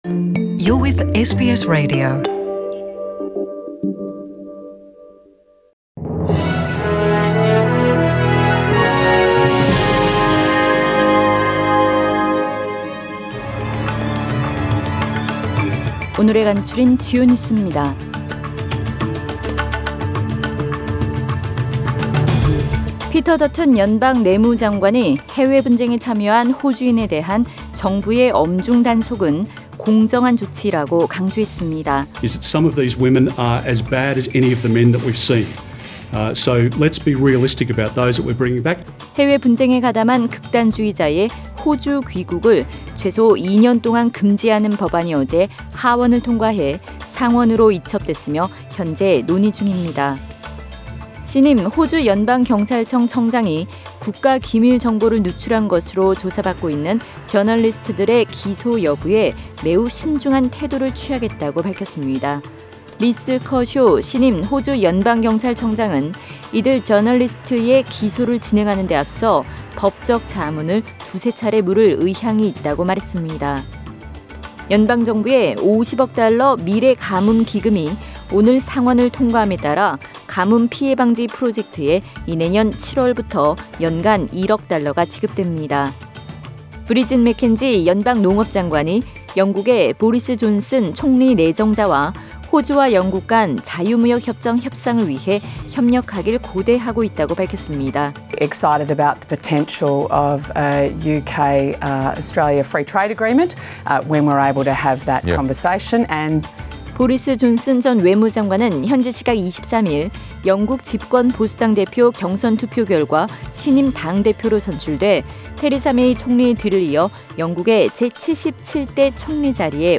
SBS 한국어 뉴스 간추린 주요 소식 – 7월 24일 수요일